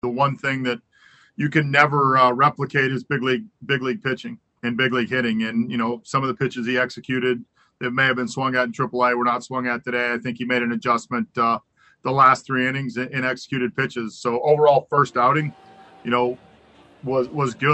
Manager Derek Shelton says Brault pitched well after settling down in the first inning.